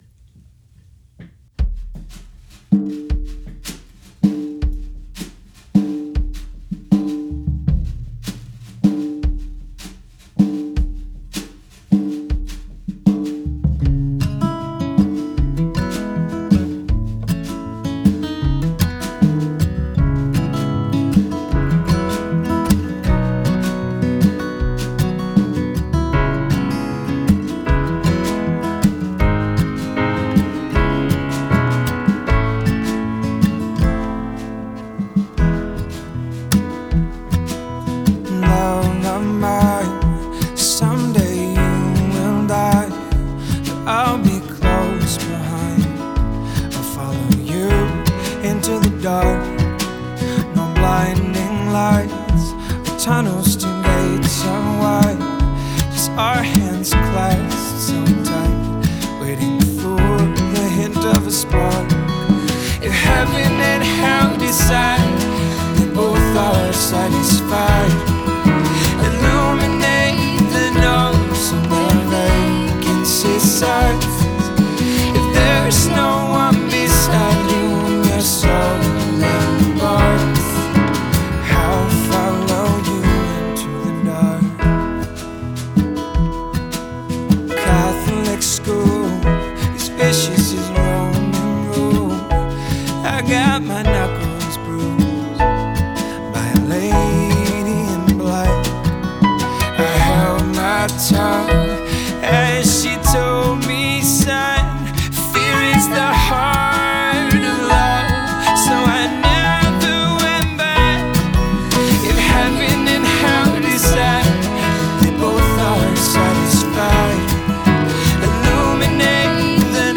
on piano